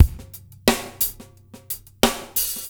SMP DRMWET-L.wav